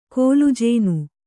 ♪ kōlu jēnu